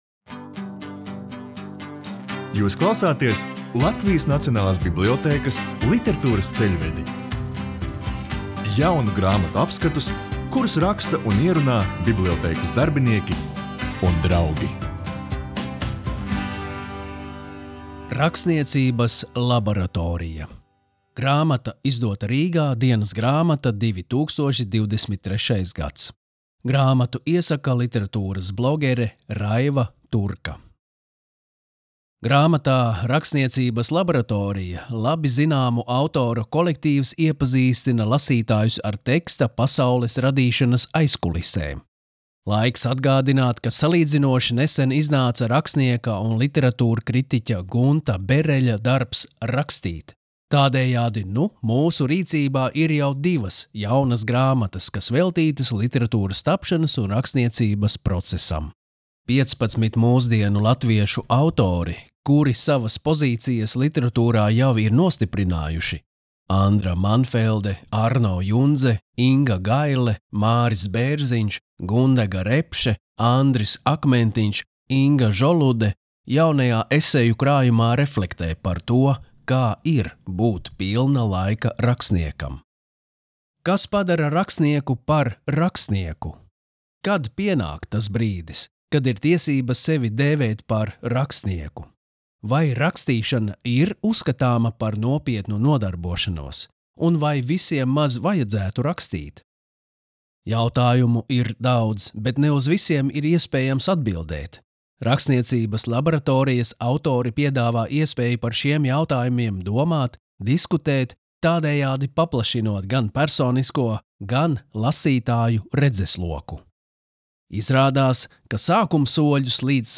Latviešu esejas
Latvijas Nacionālās bibliotēkas audio studijas ieraksti (Kolekcija)